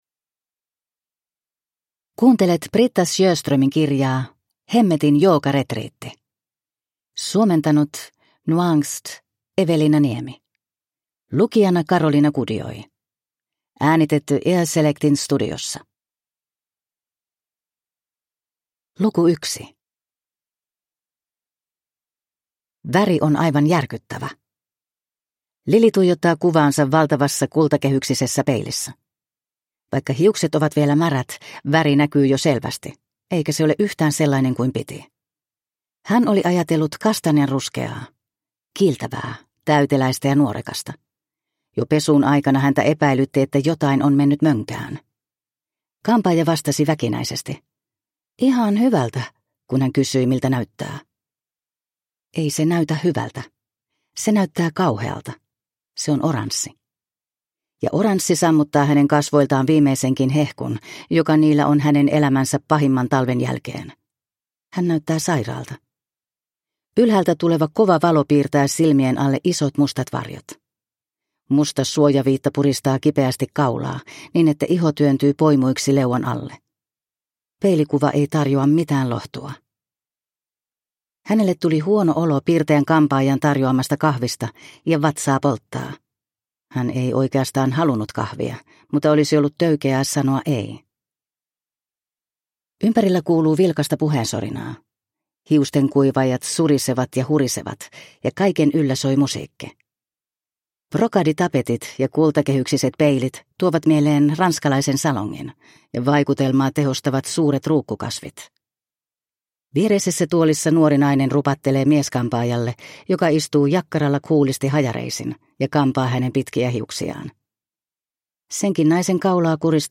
Hemmetin joogaretriitti – Ljudbok